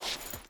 Stone Chain Jump.ogg